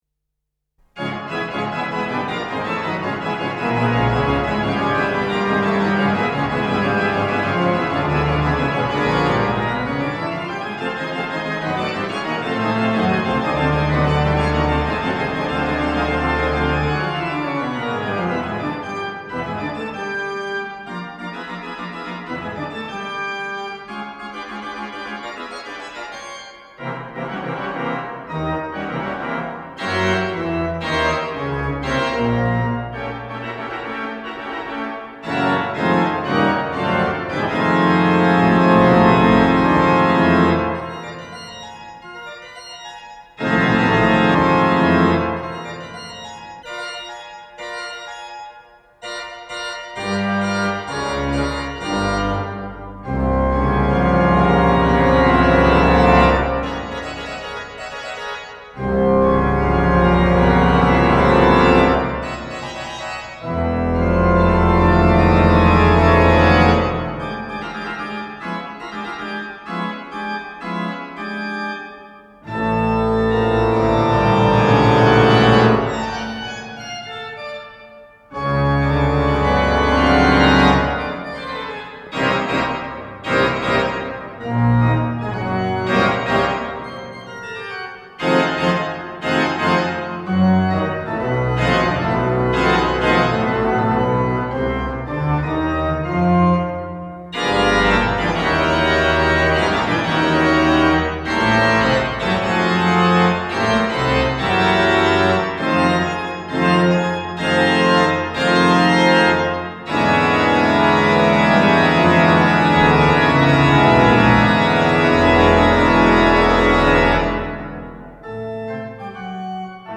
Orgel des Wiener Funkhauses